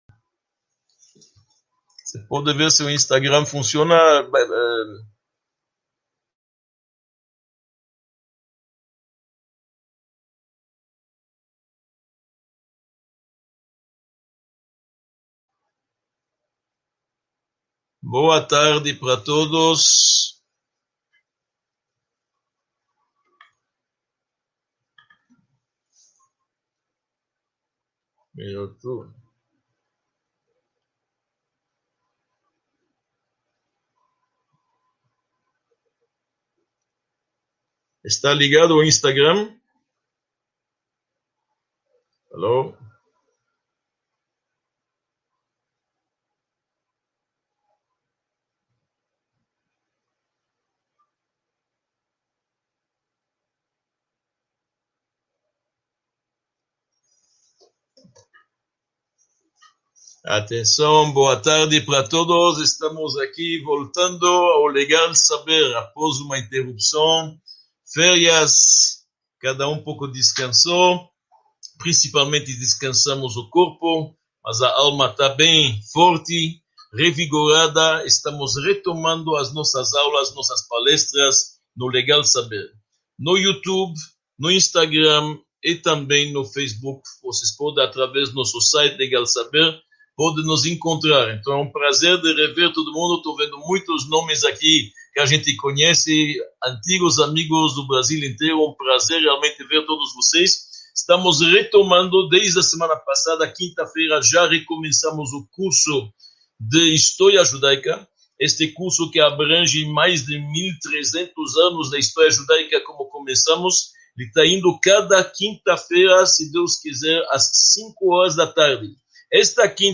19 – Medicina e Judaísmo | Módulo I – Aula 19 | Manual Judaico